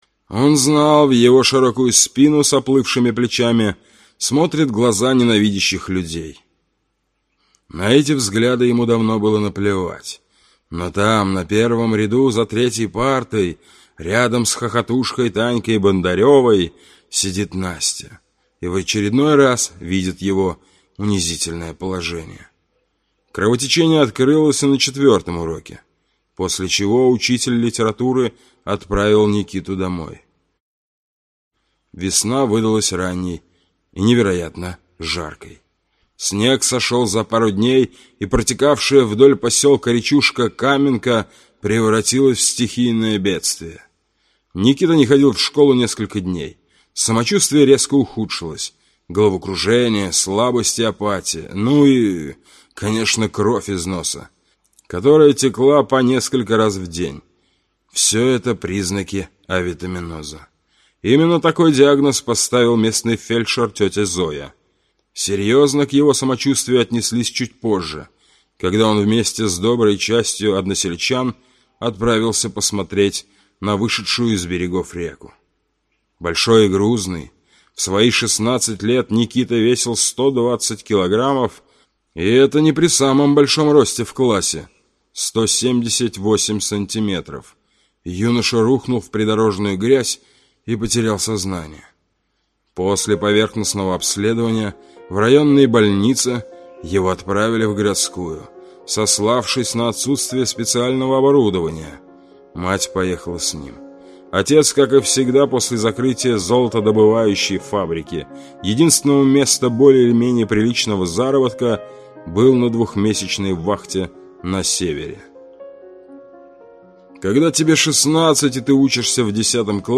Аудиокнига Со слов мертвеца | Библиотека аудиокниг
Aудиокнига Со слов мертвеца Автор Дмитрий Королевский Читает аудиокнигу